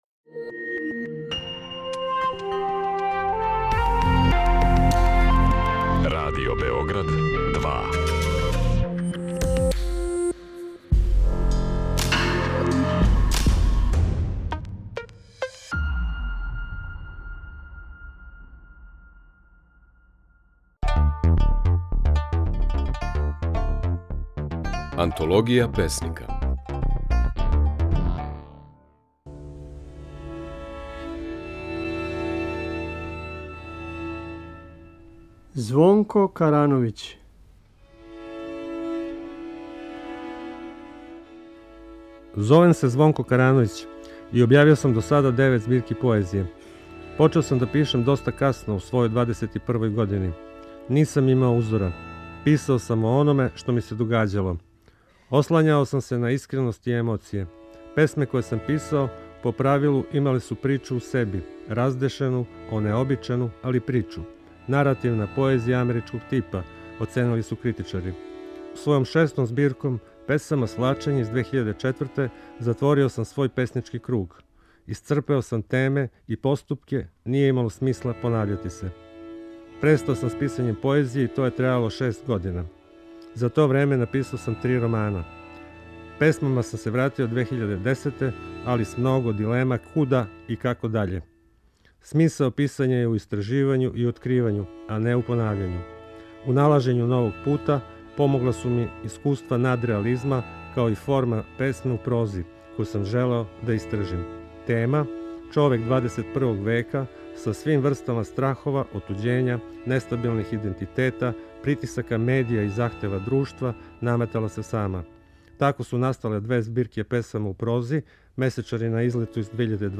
Можете чути како своје стихове говори Звонко Карановић (1959, Ниш).